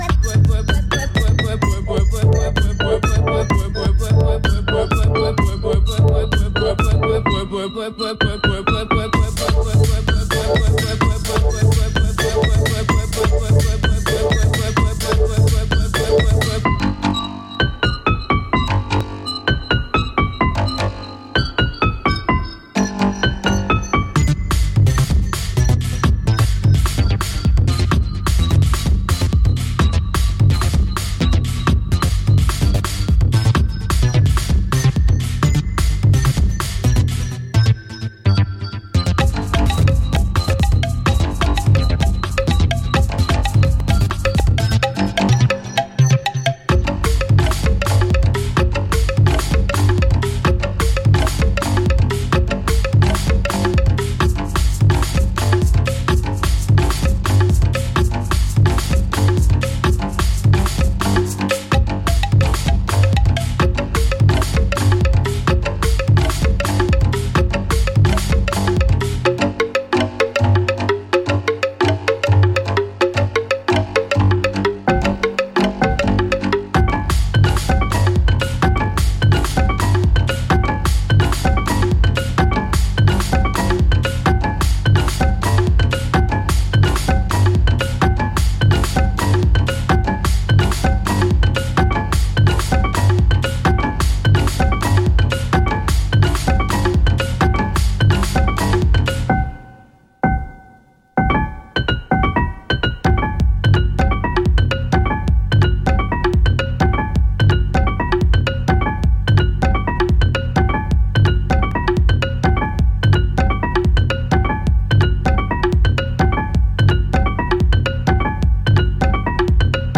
kuduro, afro-house, Angolan deep, tarraxinha, batida
Electro Electronix House Outernational